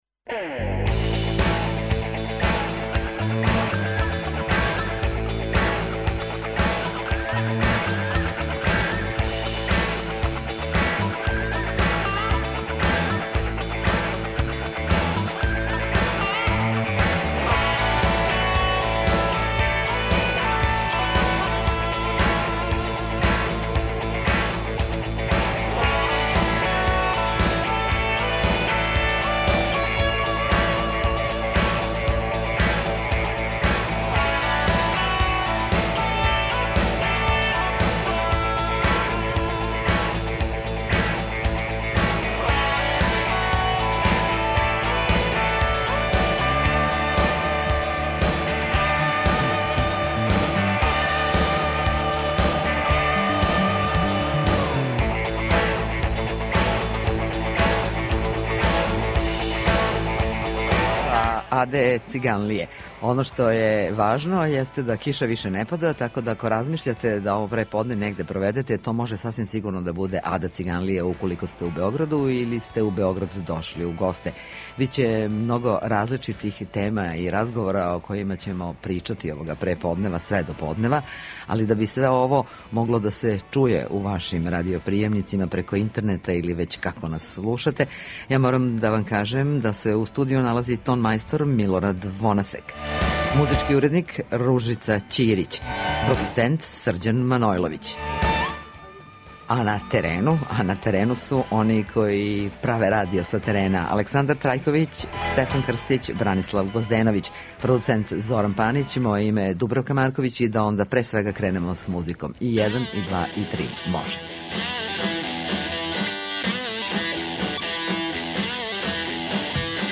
Емисија ће бити реализована са тениских терена на Ади Циганлији са пуно гостију и доста добре музике.